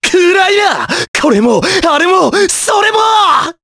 Mitra-Vox_Skill4_jp.wav